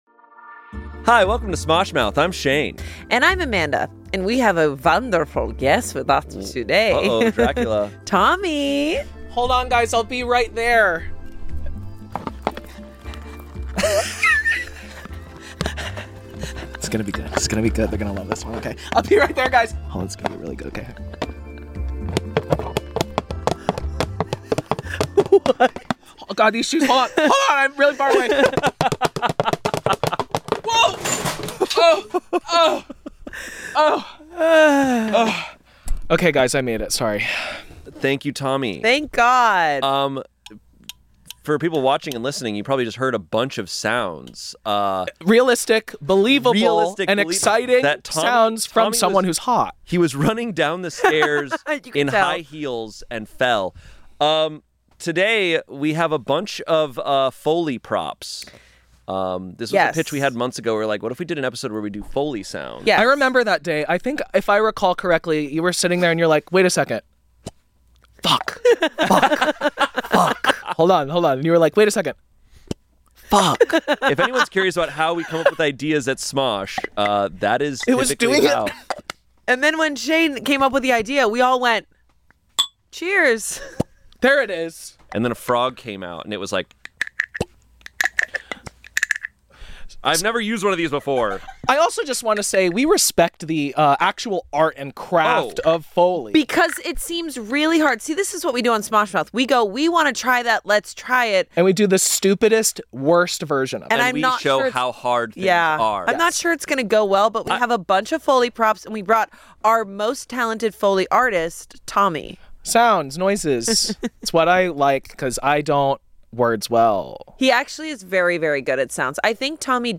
Shayne, Amanda, and Tommy try their hand at foley!